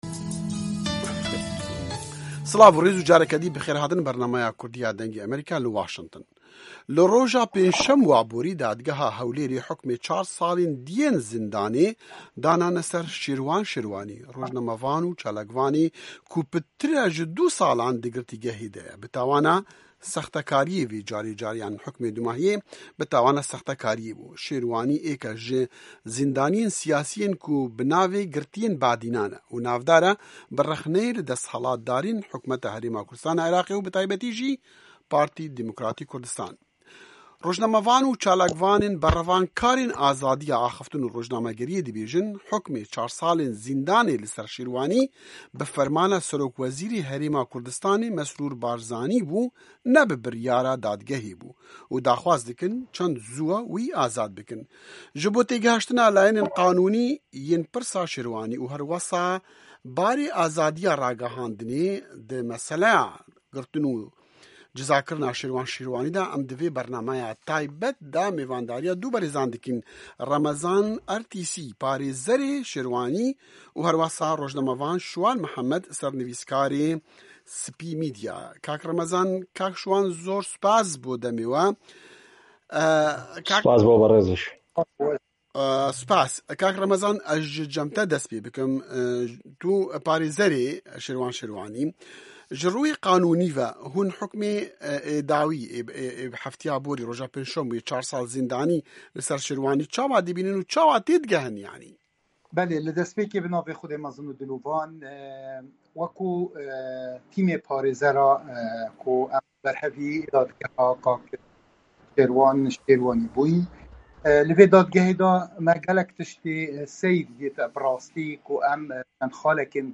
Call in show 07_25.m4a